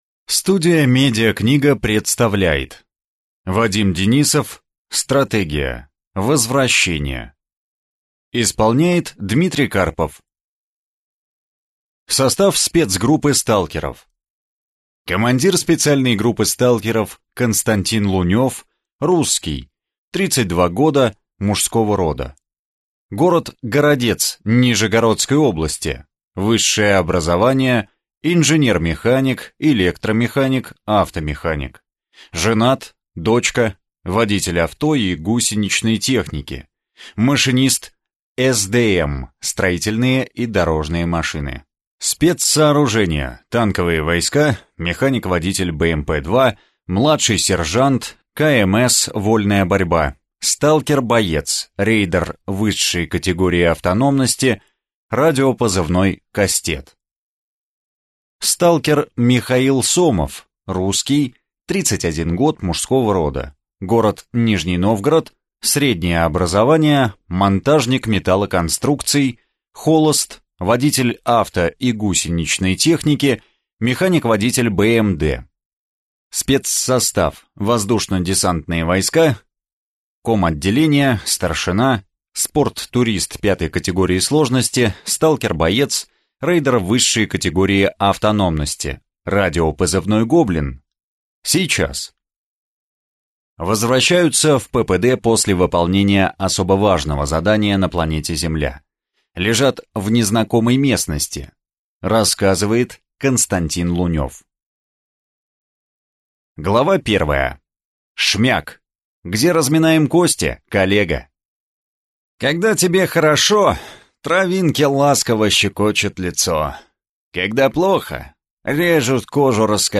Аудиокнига Стратегия. Возвращение | Библиотека аудиокниг